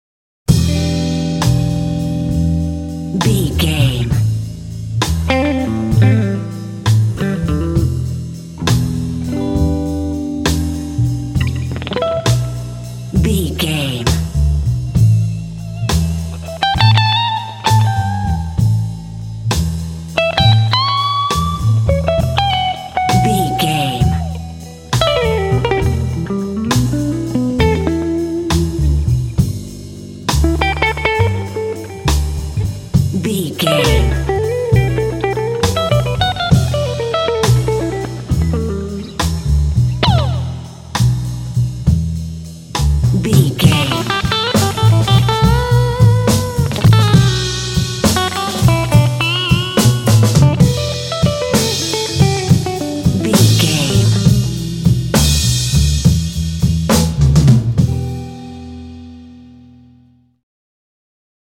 Aeolian/Minor
groovy
electric guitar
double bass
drums
jazz